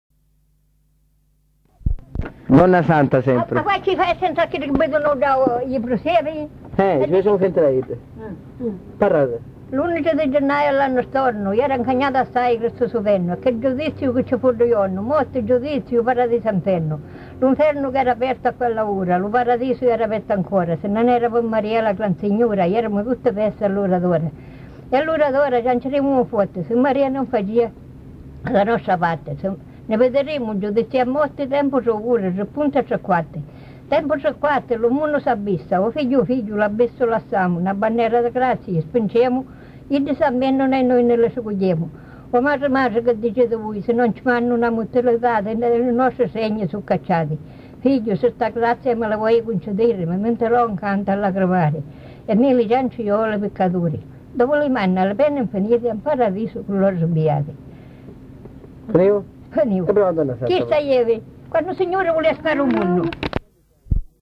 Fra il 1994 e il 1997 sono state raccolte le memorie di fatti, racconti, preghiere che si tramandavano oralmente: di seguito pubblichiamo due testimonianze rilevate nel villaggio Massa San Nicola.